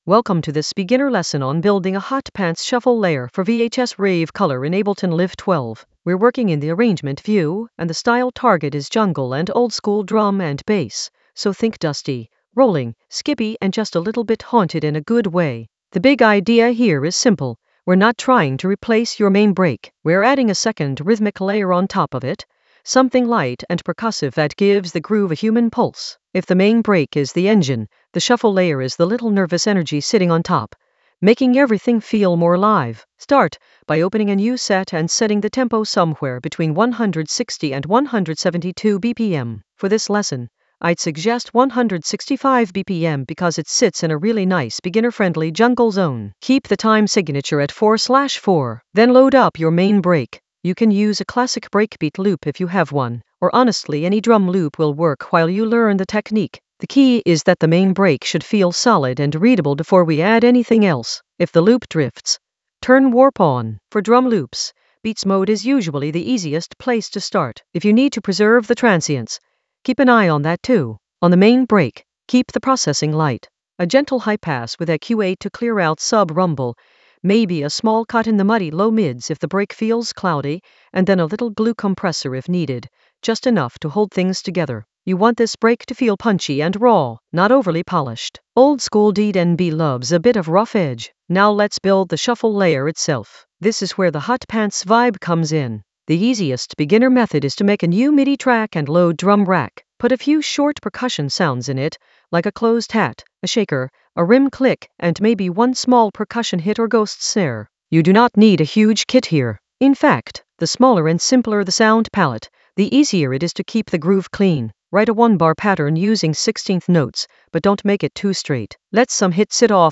An AI-generated beginner Ableton lesson focused on Hot Pants shuffle layer course for VHS-rave color in Ableton Live 12 for jungle oldskool DnB vibes in the Arrangement area of drum and bass production.
Narrated lesson audio
The voice track includes the tutorial plus extra teacher commentary.